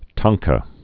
(tängkə)